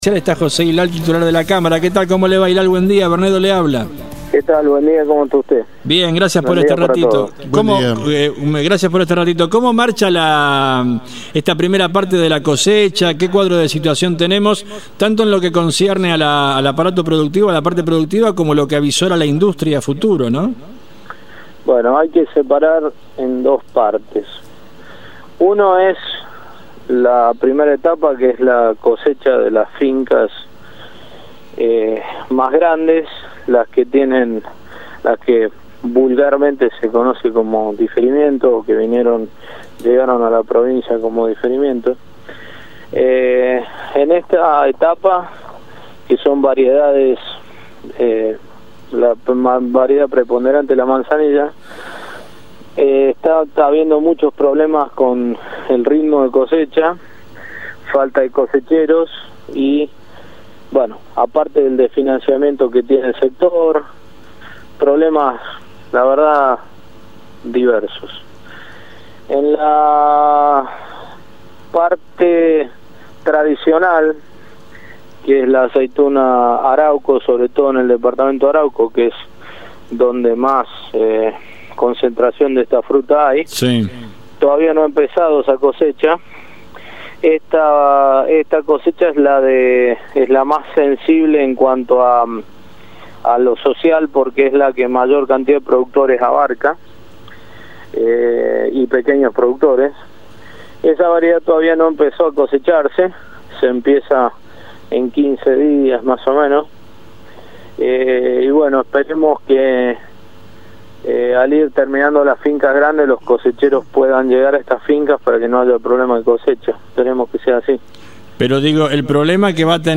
En diálogo con Radio Libertad